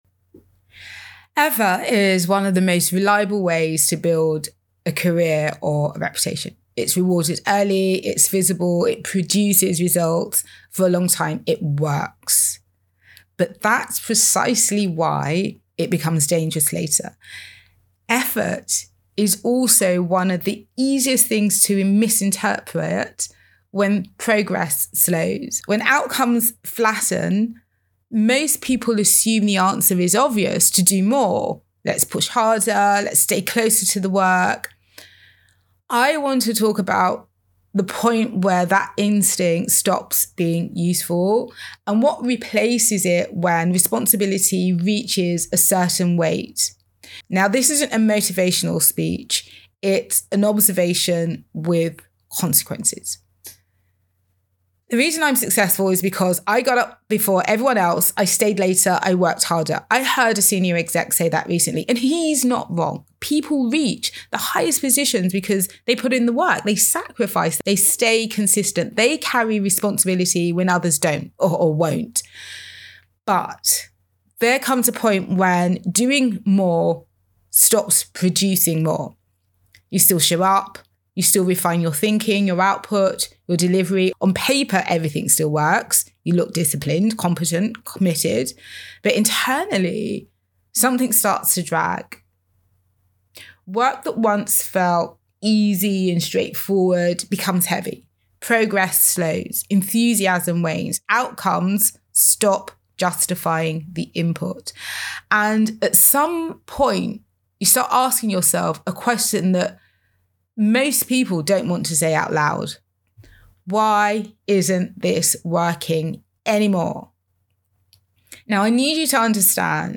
Audio Commentary
when-effort-stops-working-audio-commentary.mp3